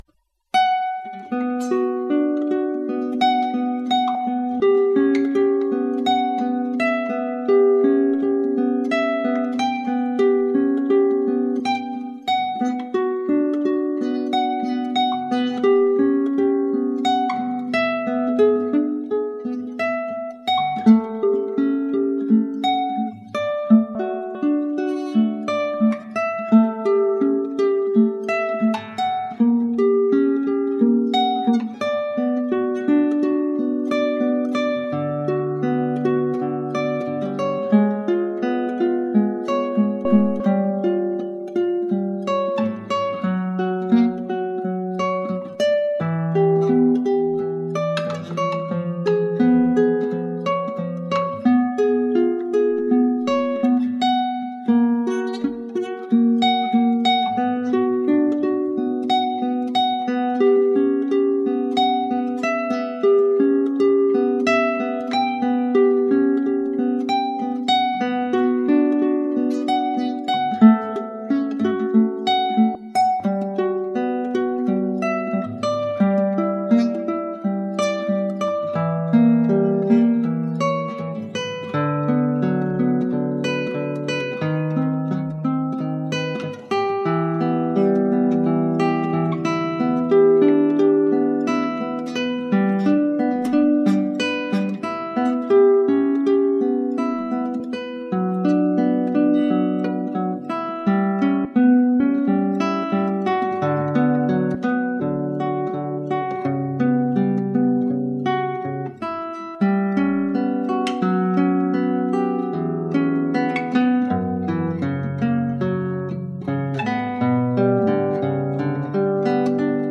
Prélude de la Catedral - Barrios - Guitare Classique
Passez sur les quelques BZZZZ que je n'ai pu éviter dans ce morceau assez difficile en haut du manche.
Une très belle interprétation, on entend très distinctement la voie basse et l'aigu, le timbre employé est très sensuel!